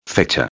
•\ [fe·cha] \•